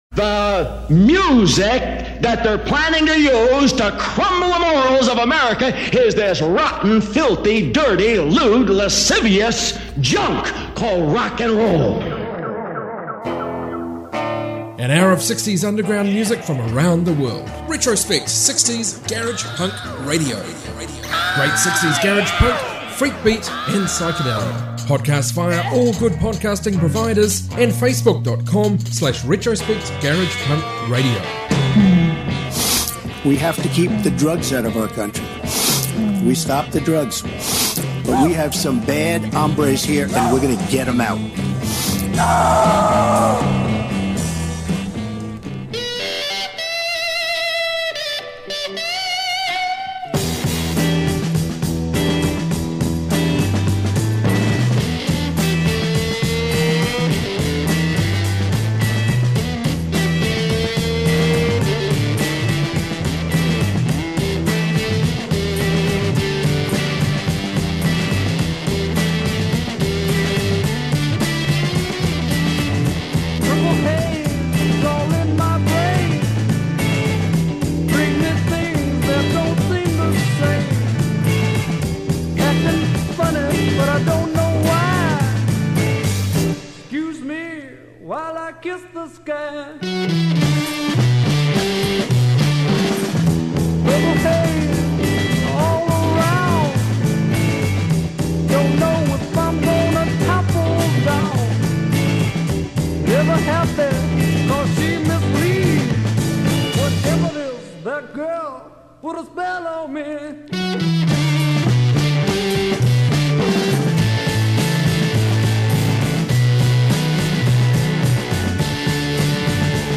global 60s garage punk, freakbeat